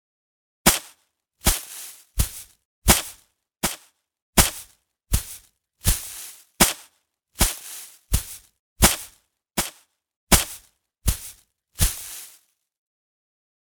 Звуки бани
Погрузитесь в атмосферу настоящей русской бани с подборкой звуков: треск дров, плеск воды на каменку, шипение пара.
Звук веником в бане парятся хлещут